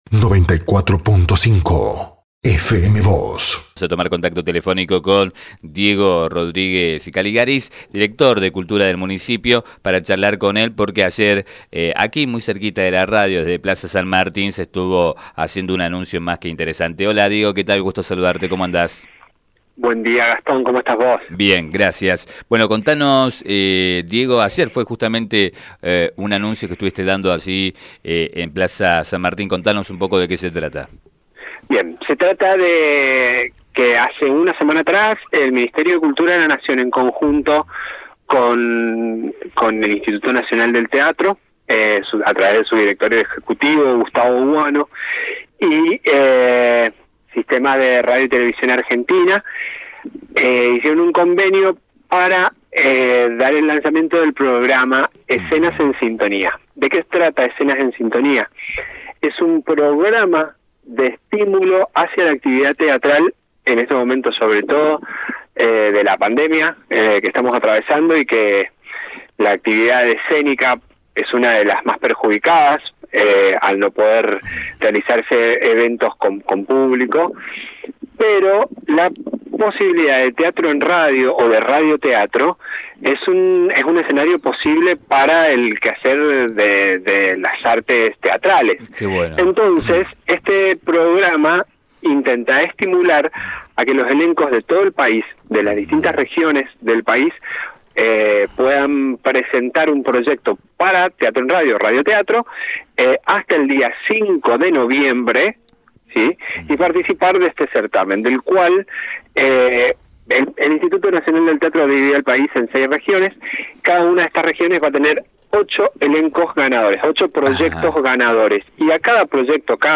En diálogo con FM Vos (94.5) y nuestro diario, el director de Cultura de San Rafael, Diego Rodríguez Caligaris, explicó que “Escenas en sintonía” es un programa de estímulo hacia la actividad teatral, ante la complejidad que ha implicado la pandemia para la actividad escénica en general al no poderse realizar eventos con público.